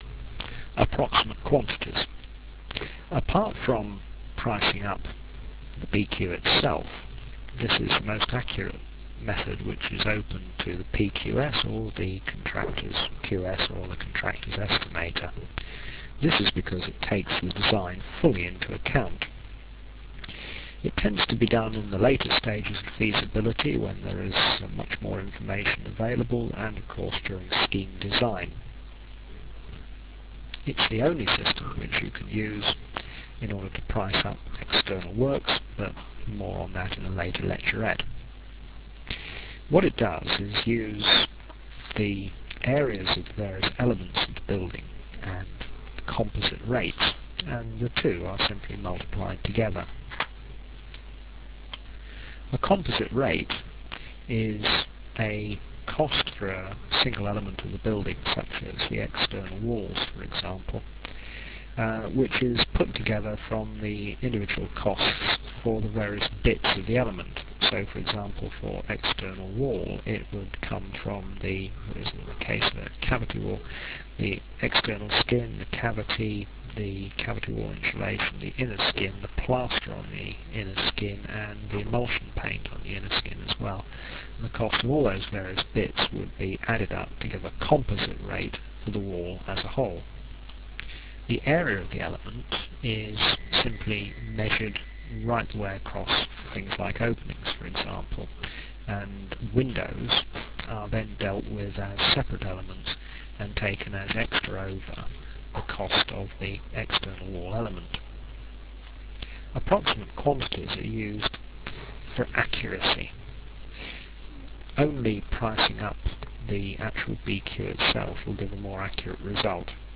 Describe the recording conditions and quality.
The sound quality is adequate but low fi.